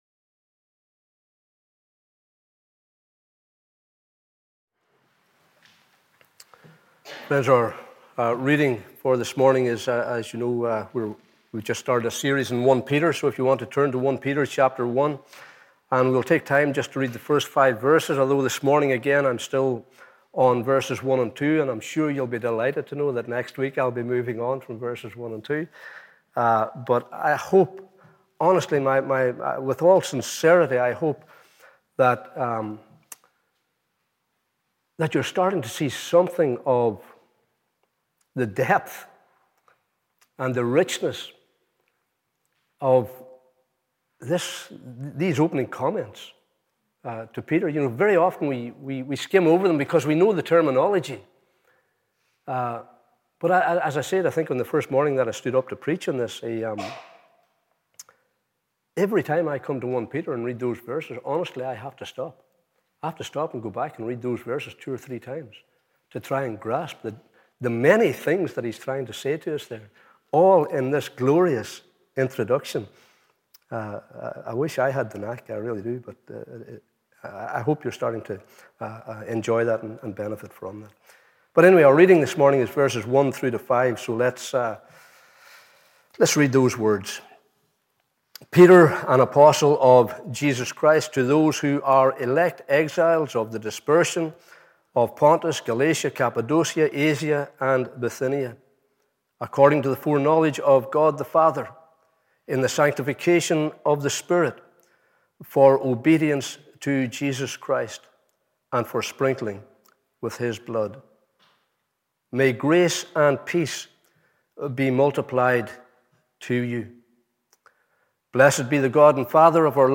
Morning Service 17th October 2021